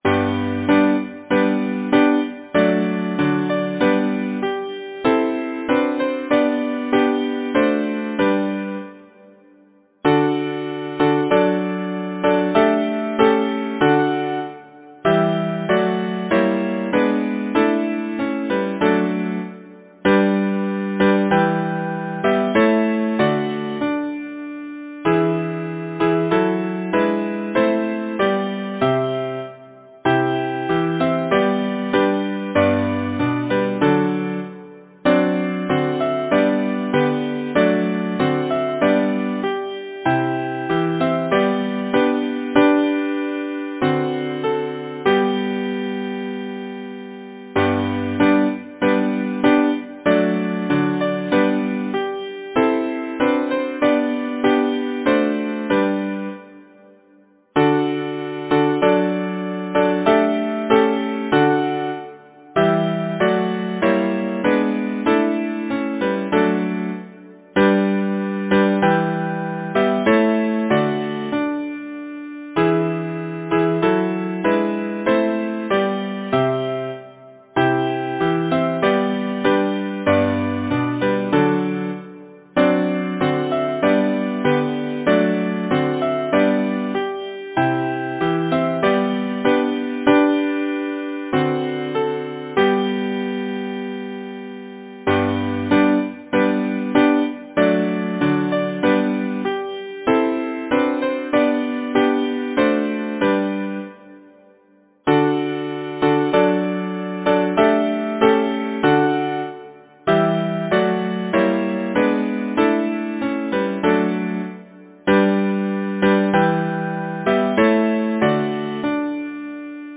Title: Higher, higher will we climb Composer: Henry Lahee Lyricist: James Montgomery Number of voices: 4vv Voicing: SATB Genre: Secular, Partsong
Language: English Instruments: A cappella